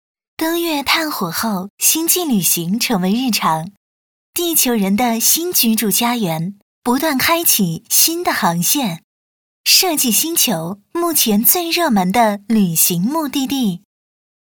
女12号配音师
声音年轻、知性、甜美。
老师宣言：认真对待每一个作品 代表作品 Nice voices 播报 广告 角色 解说 童音 旁白 专题片 播报-女12-星际.mp3 复制链接 下载